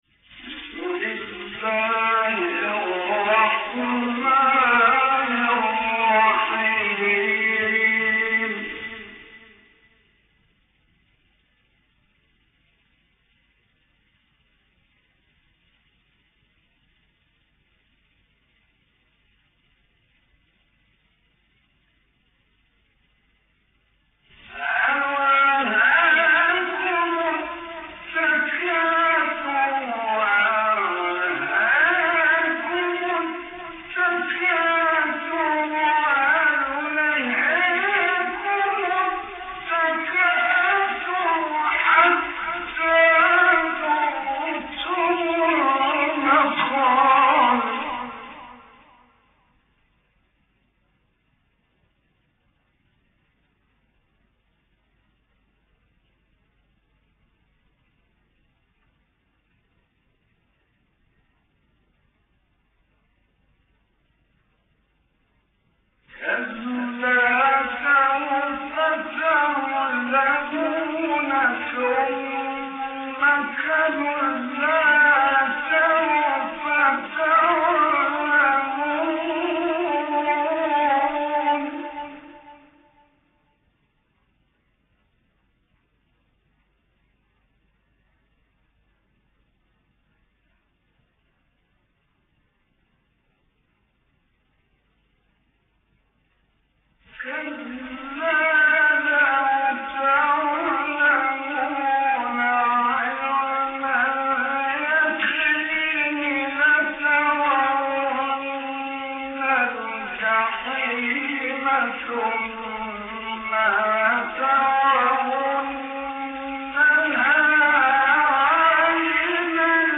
تلاوت سوره تکاثر توسط استاد محمد رفعت | نغمات قرآن | دانلود تلاوت قرآن